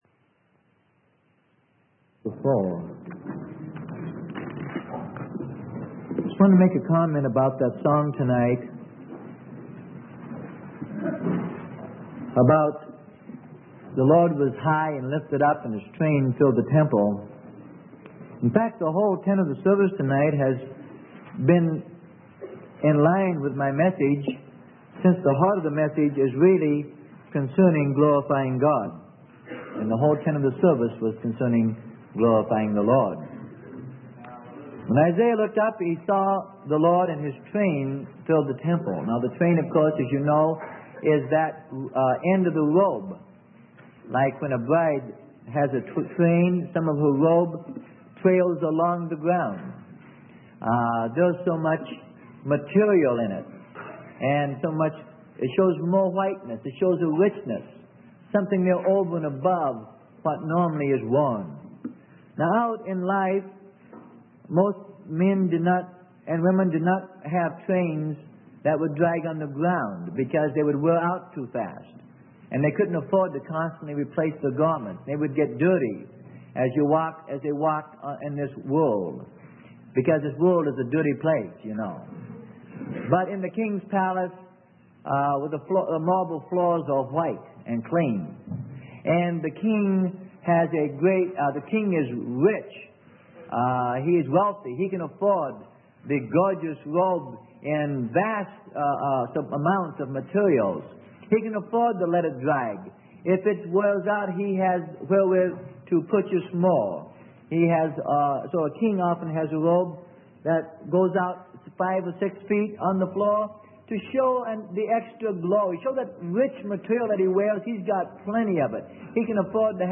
Sermon: Steal No More - Ephesians 4:20 - Freely Given Online Library